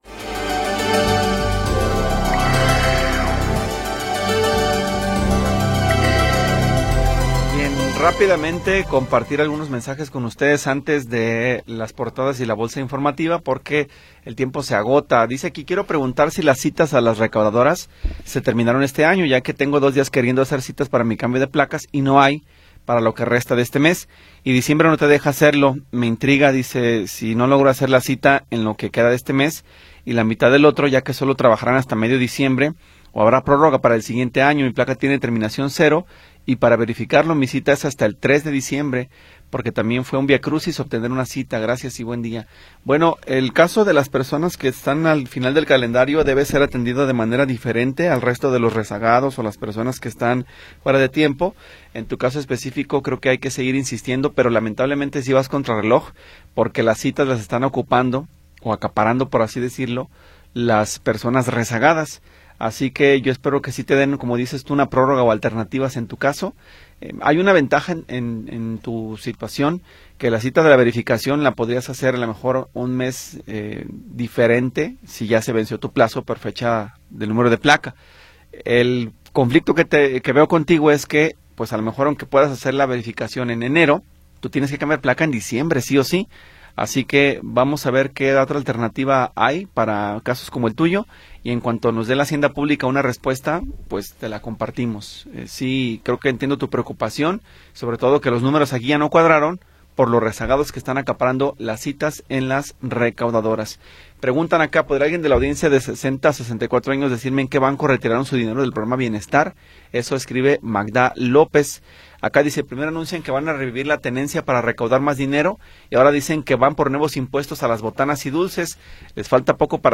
Información oportuna y entrevistas de interés
Tercera hora del programa transmitido el 21 de Noviembre de 2025.